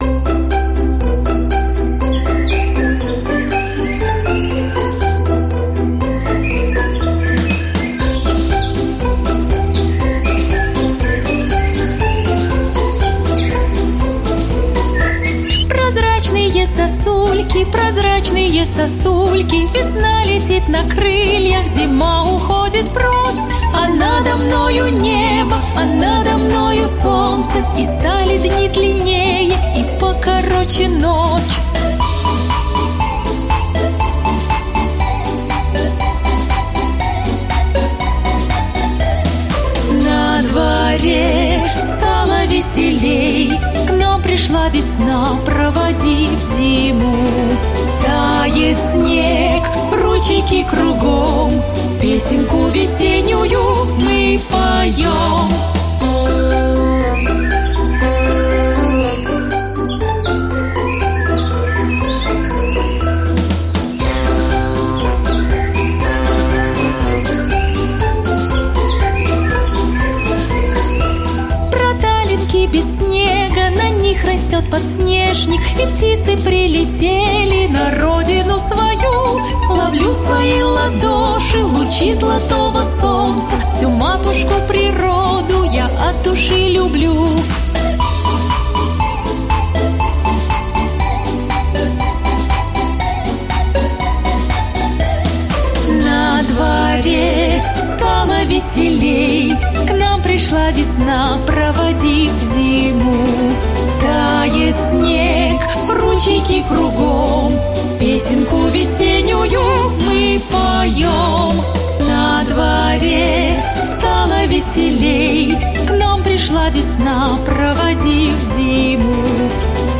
Песенки про весну